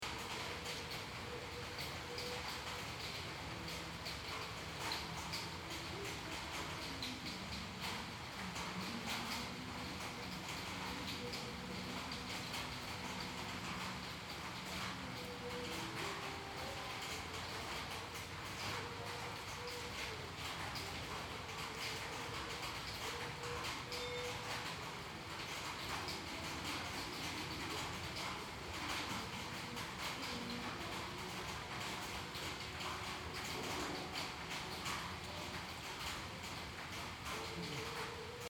08/04/2014 17:00 En fin d'après-midi nous sommes surpris par un orage alors que nous nous balladons en vélo sur la berge. Nous nous abritons dans un petit restaurant vide, où nous savourons un délicieux café en écoutant les gouttes de pluie éclater sur la tole ondulée.